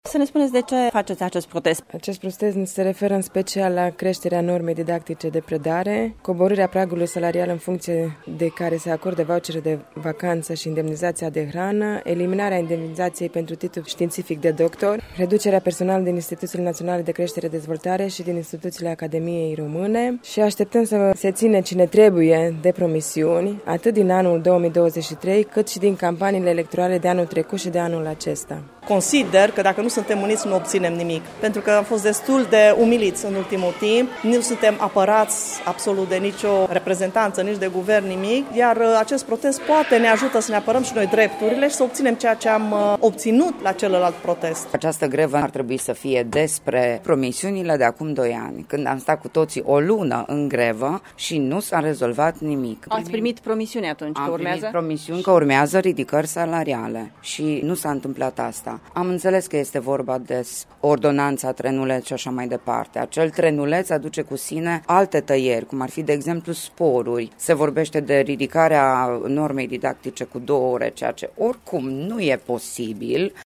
Dascălii de la Școala Gimnazială ”George Coșbuc” din Târgu Mureș sunt solidari cu protestatarii din întreaga țară și spun că prioritar în acest protest este respectarea promisiunilor neonorate de acum doi ani: